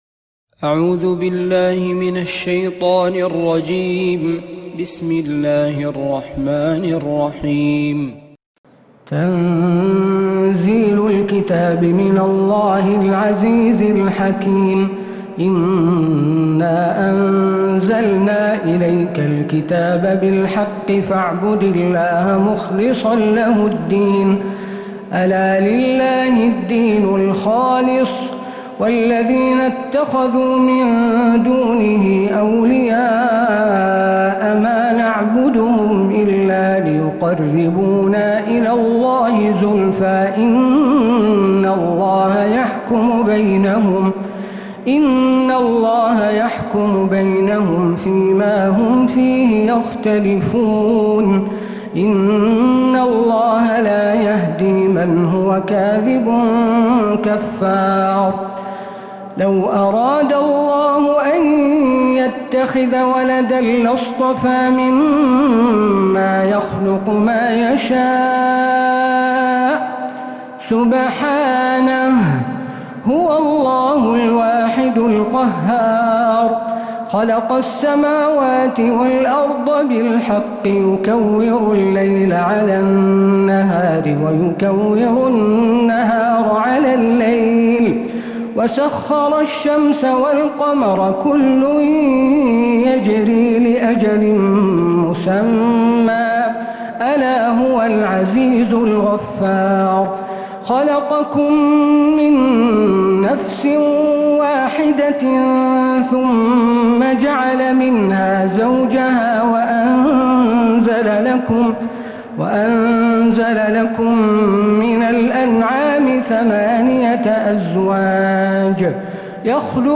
سورة الزمر من تراويح الحرم النبوي